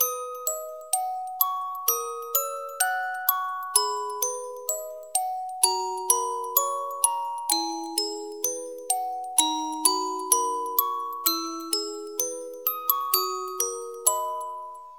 ファミコン風音楽素材です。